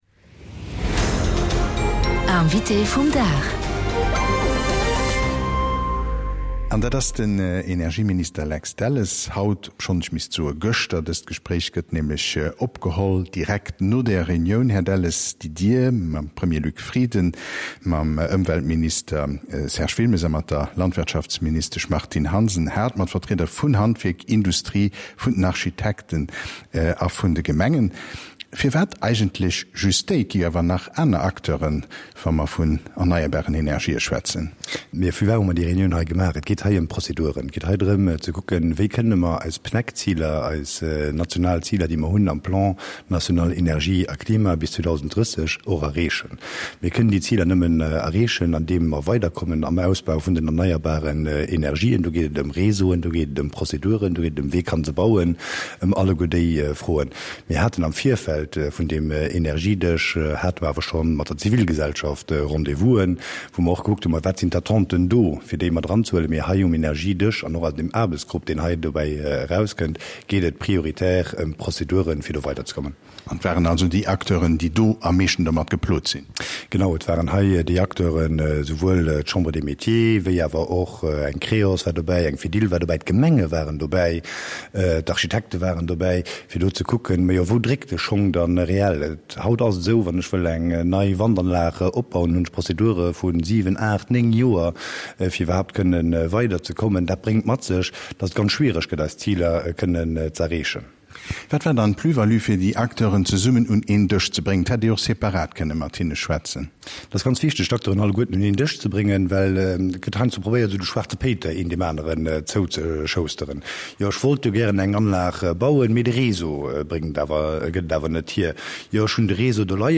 Et bestéing enorme Potential hei am Land fir laanscht d’Autobunne Solaranlagen ze bauen. Dat seet den Energieminister Lex Delles am 100,7 -Interview, an der Suite vum Energiedësch e Mëttwoch zu Senneng.